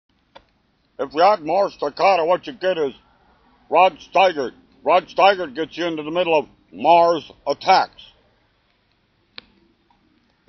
Doing WC FIELDS while doing too fast I noticed who it sounded like.